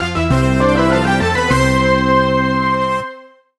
Game Music